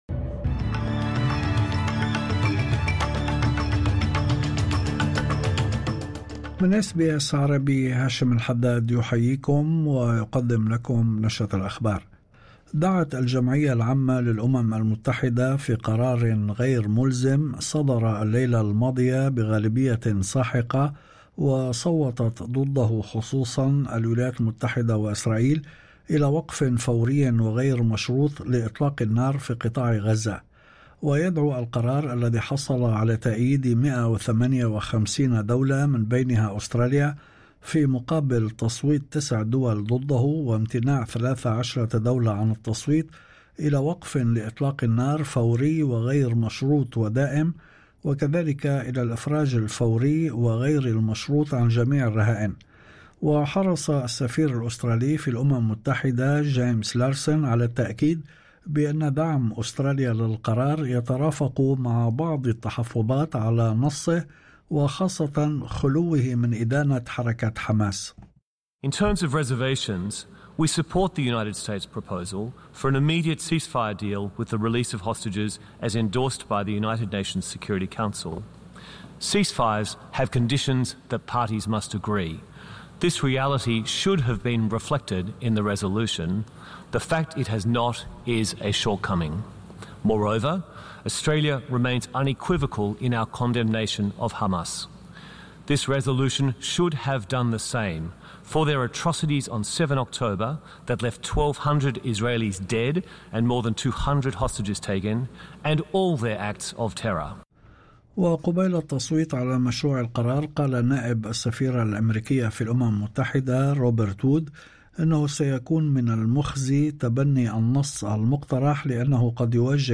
نشرة أخبار الظهيرة 12/12/2024